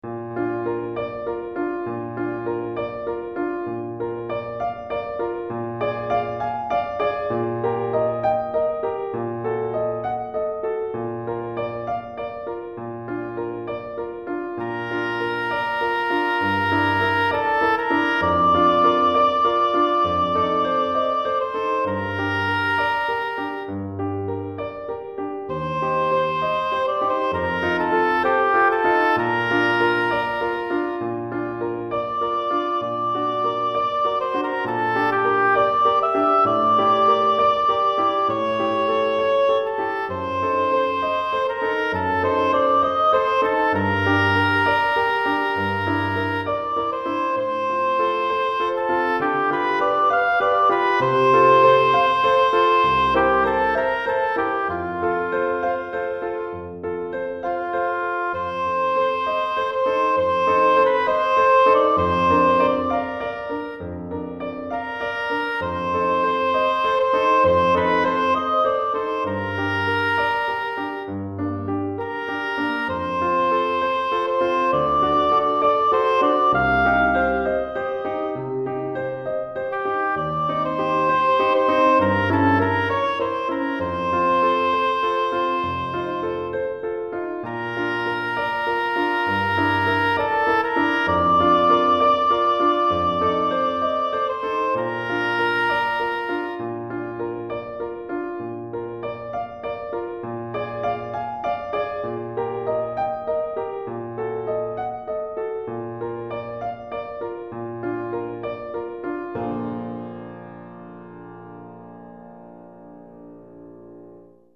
Hautbois et Piano